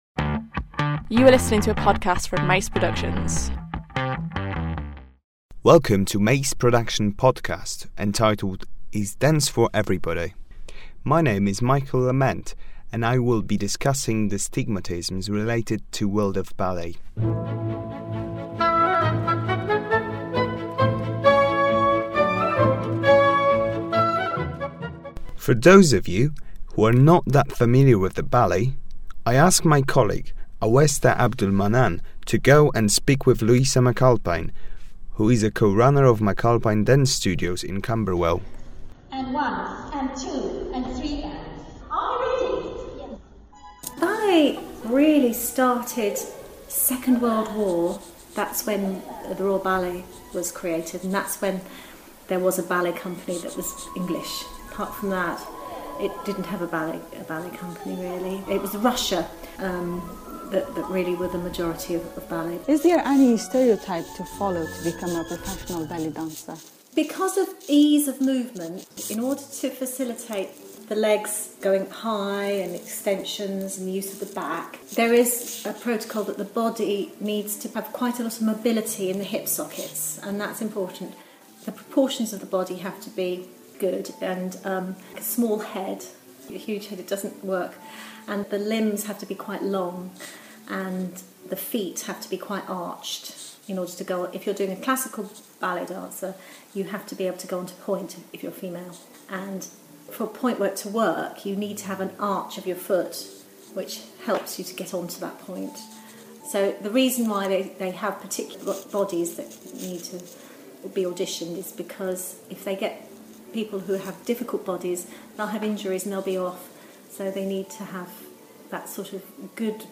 A 10 minute podcast introducing and discussing the strict requirements found in the world of ballet. From ethnic background to body anatomy we will interviewing working professionals, graduates well under way in their studies and heads of established institutes in order to find out how tough the selection process has been and how it is steadily becoming more diverse.